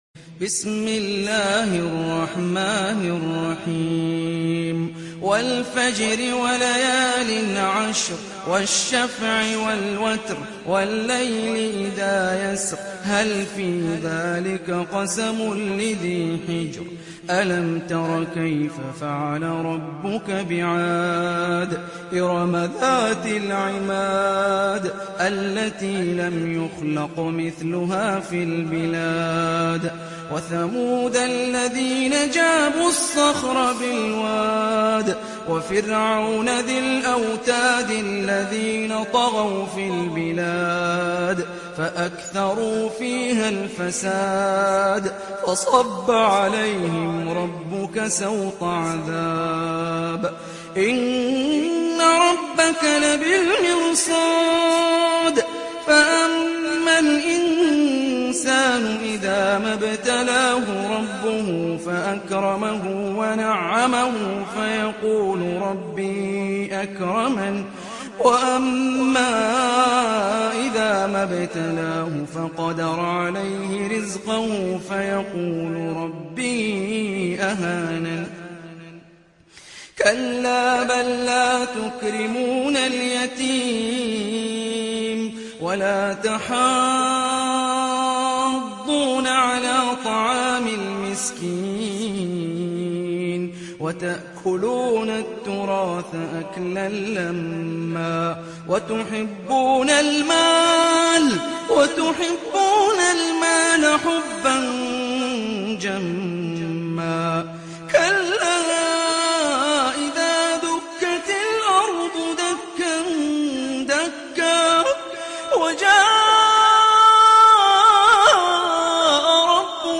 Sourate Al Fajr Télécharger mp3 Hani Rifai Riwayat Hafs an Assim, Téléchargez le Coran et écoutez les liens directs complets mp3